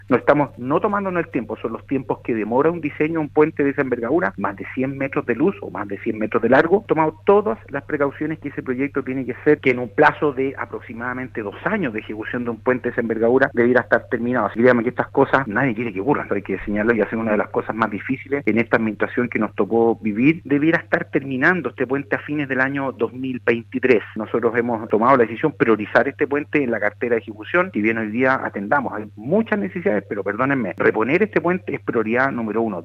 En conversación con el programa Haciendo Ciudad de Radio Sago, el Seremi del Ministerio de Obras Públicas, James Fry abordó el desplome de este puente el 23 de junio del 2018 y que dejó a una persona fallecida y seis heridos, además de la destitución de diversos cargos del Ministerio y el inicio de acciones legales.